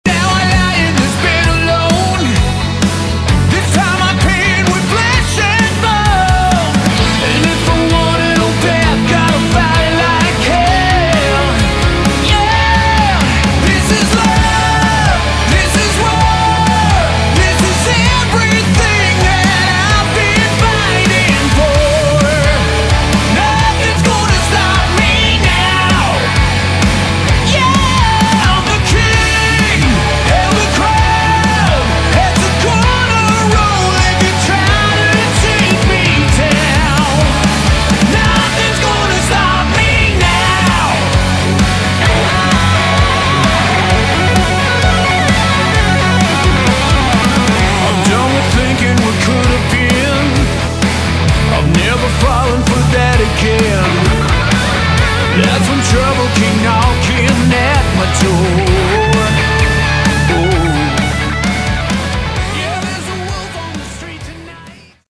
Category: AOR / Melodic Rock
bass
vocals, guitar
drums